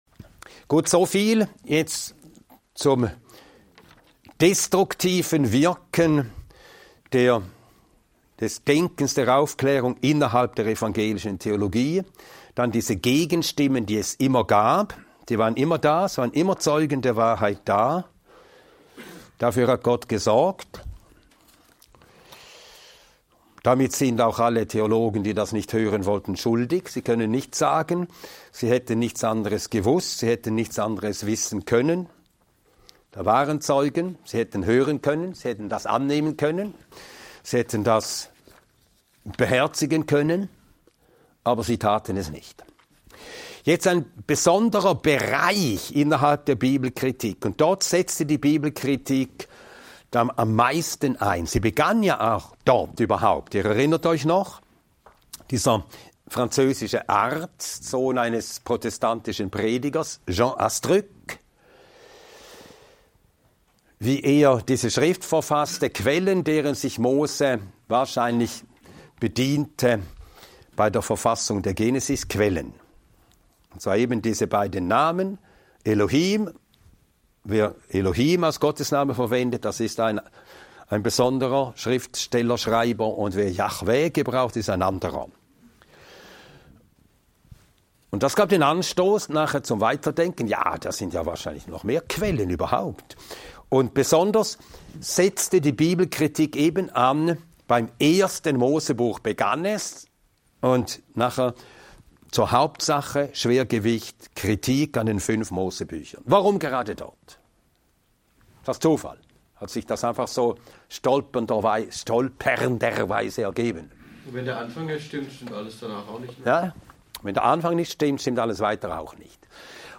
Ein mehrteiliger Vortrag